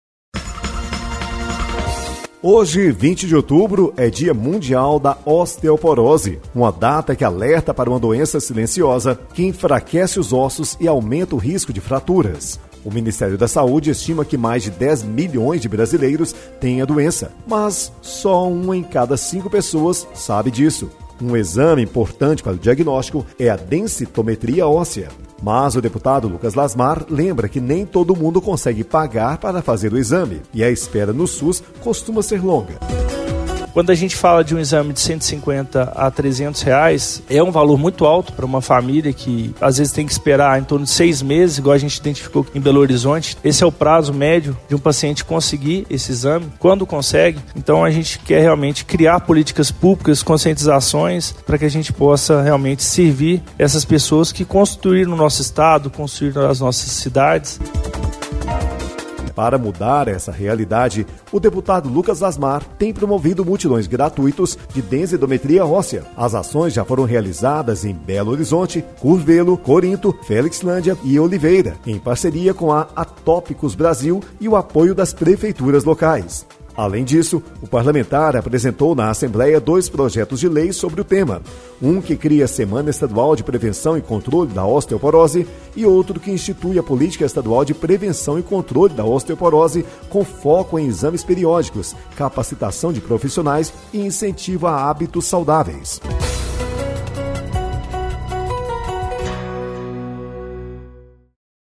Boletim de Rádio